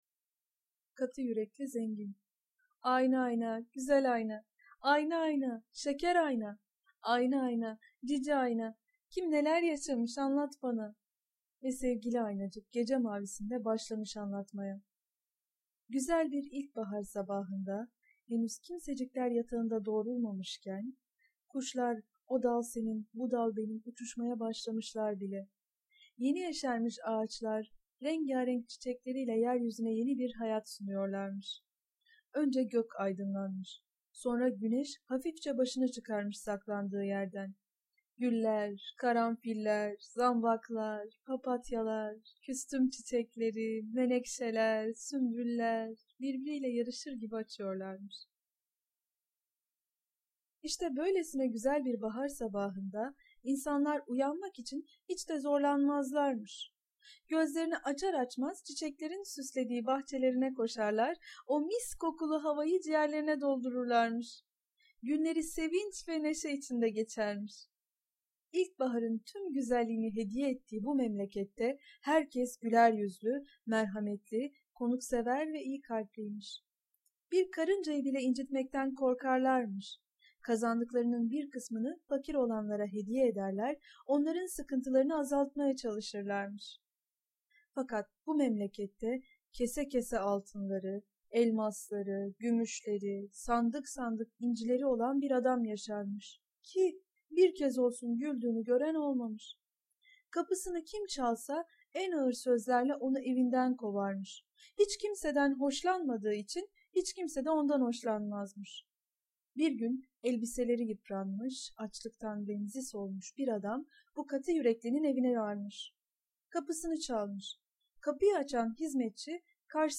Katı yürekli zengin sesli masalı, mp3 dinle indir
Sesli Çocuk Masalları